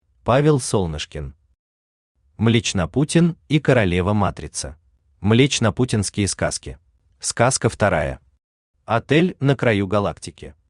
Аудиокнига Млечнопутин и Королева-Матрица | Библиотека аудиокниг
Aудиокнига Млечнопутин и Королева-Матрица Автор Павел Солнышкин Читает аудиокнигу Авточтец ЛитРес.